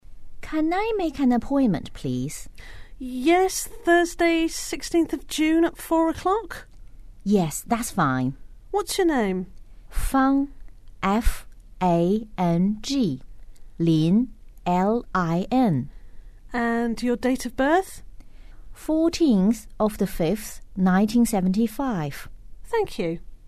英语初学者口语对话第79集：我能预约看病吗？